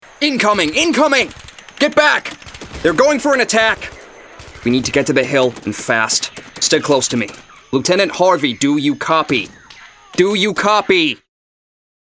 • Male
US Soldier. Audio Book, Enthusiastic, Authoritative